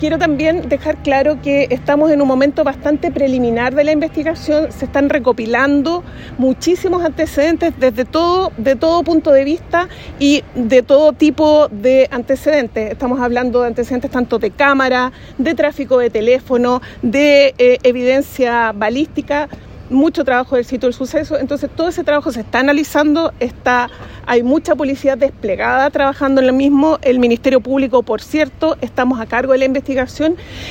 La fiscal Regional de Los Lagos, Carmen Gloria Wittwer, entregó la indagatoria a la recién estrenada Fiscalía ECOH (Equipo Contra el Crimen Organizado y Homicidios) y explicó la relevancia que tiene haber declarado secreta la investigación.